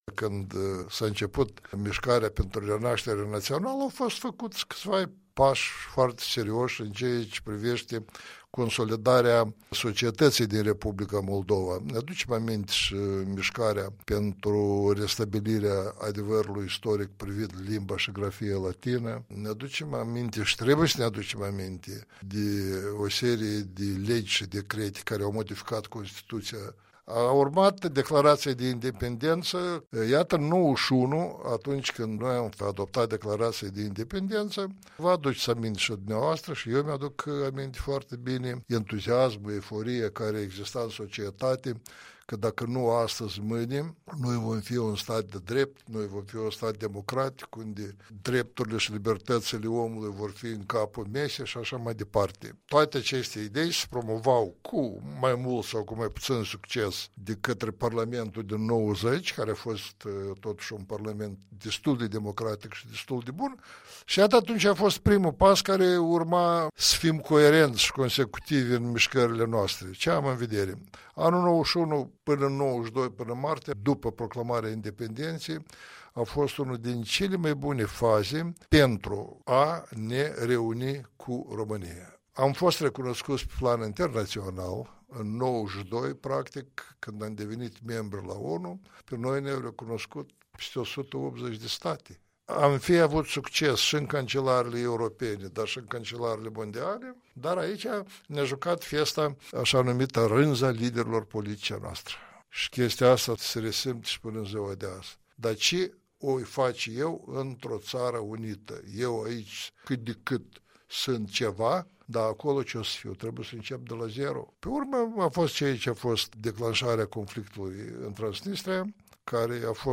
Interviu cu fostul judecător la Curtea Constituională Nicolae Osmochescu despre cei 27 de ani de independenţă a Republicii Moldova.